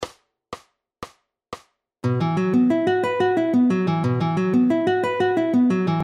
Esus2 トライアド
コンテンポラリー,ジャズギター,トライアド,sus2,アドリブ